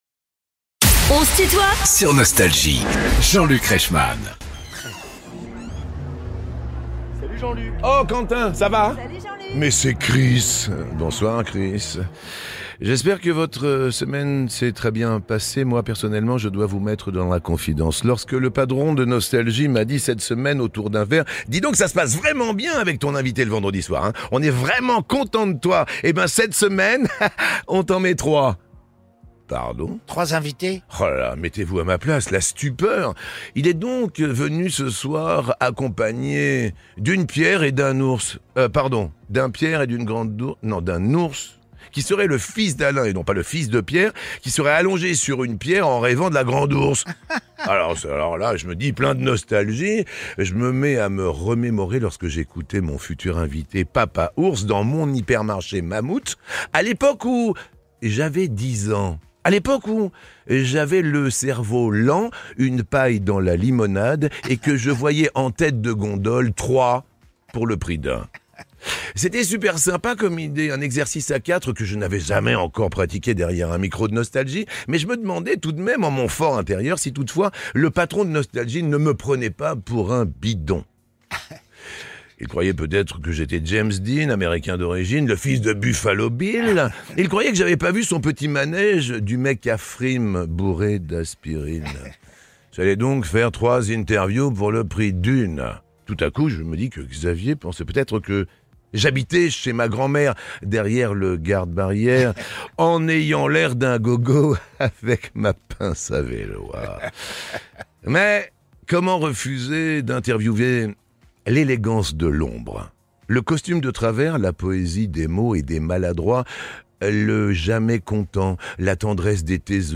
Alain Souchon et ses fils sont les invités de "On se tutoie ?..." avec Jean-Luc Reichmann (Partie 1) ~ Les interviews Podcast